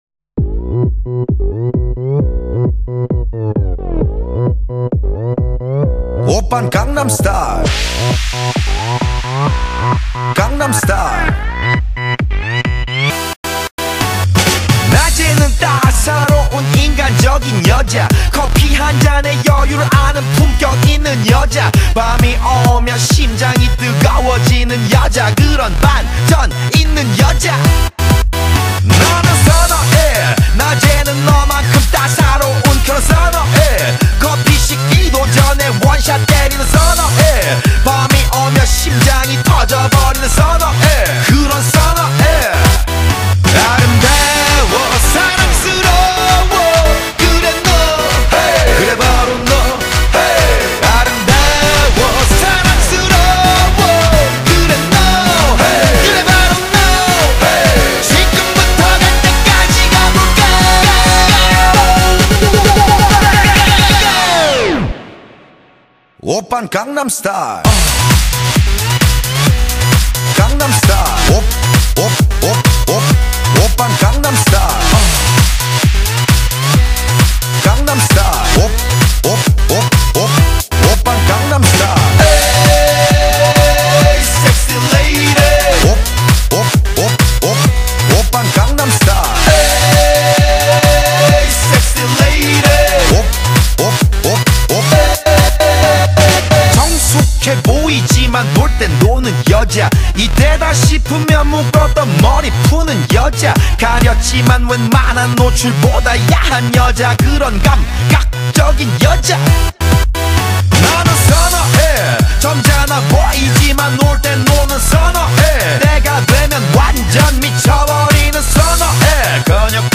Genre: Pop, Soul, R&B, Rap